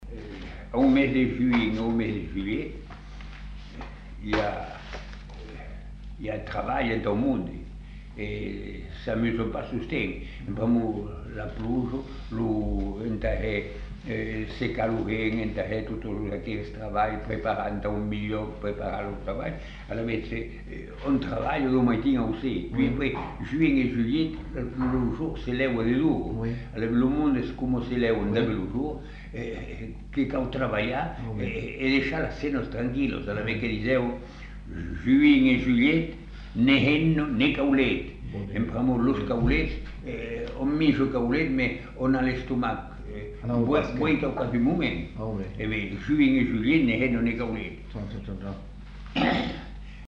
Aire culturelle : Savès
Effectif : 1
Type de voix : voix d'homme
Production du son : récité
Classification : proverbe-dicton